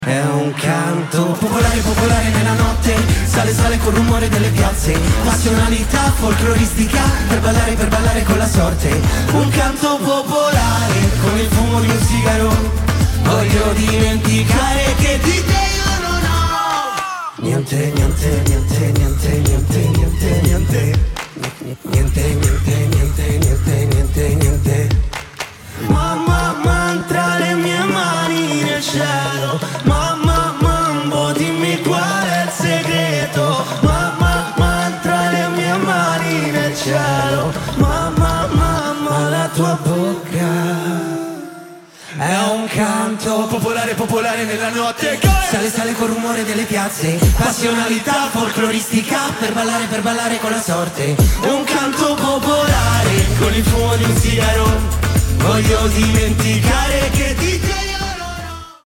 la kermesse musicale dell'estate italiana